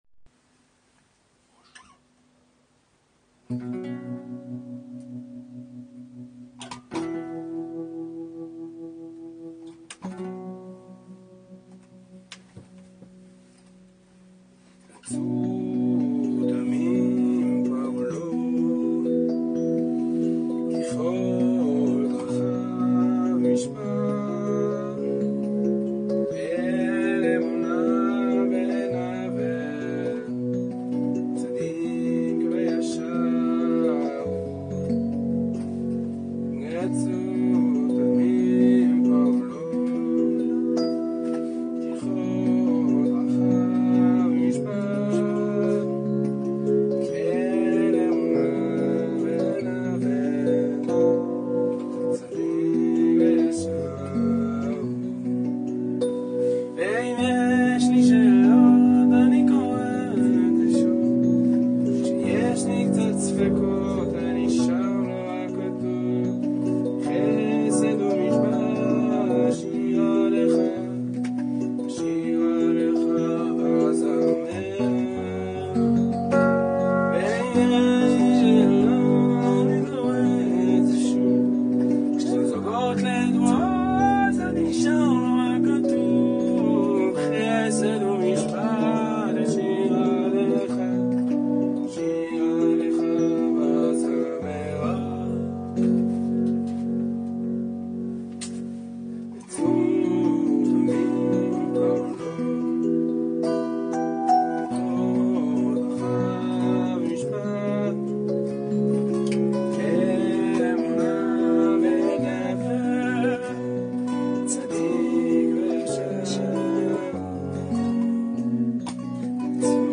האלן חברה אנחנו סמוך לפרשת האזינו הלחנתי שיר לפני שנתיים על האמונה התמימה (עיבוד פשוט) אשמח לקבל חוות דעת ותגובות תודה אין עליכם!
מאוד יפה ונעים סגנון ומשקל בין חסידי לישראלי